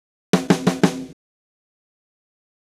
Drumset Fill 22.wav